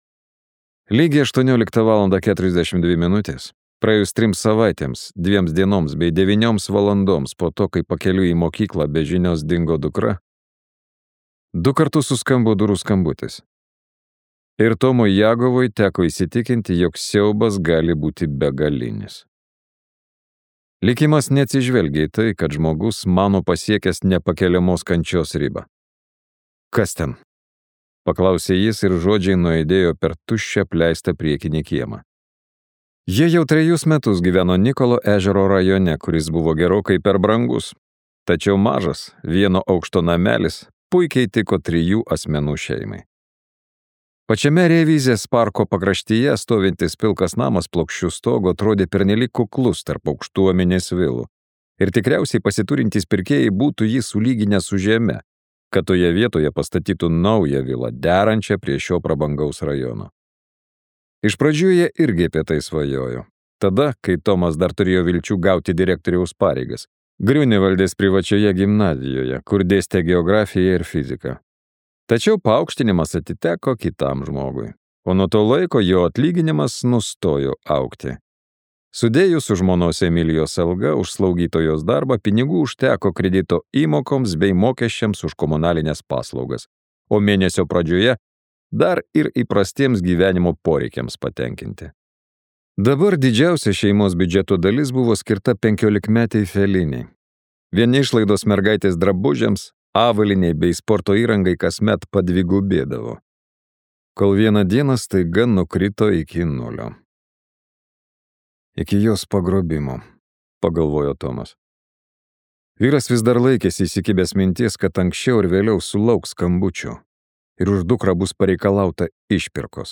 Sebastian Fitzek audioknygoje „Grojaraštis“ detektyvas Aleksandras Corbachas tiria paslaptingą mergaitės dingimą.